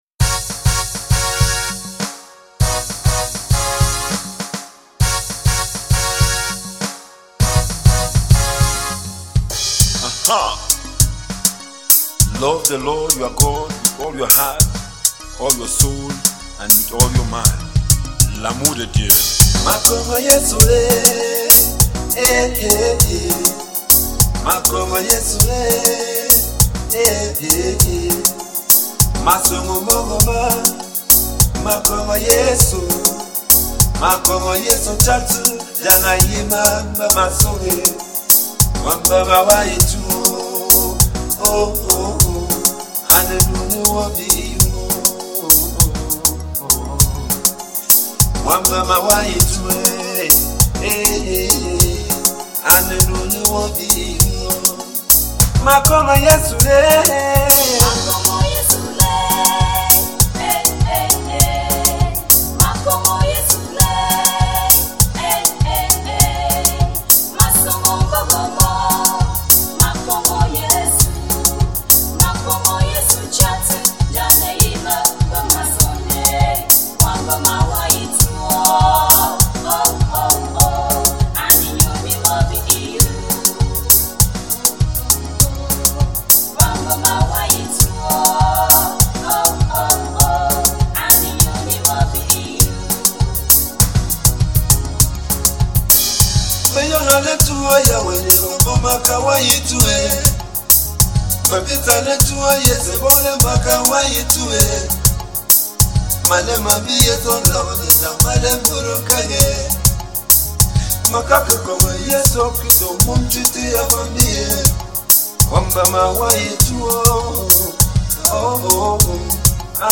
Bafut Gospel Songs | Bafut